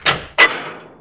clankybutton.wav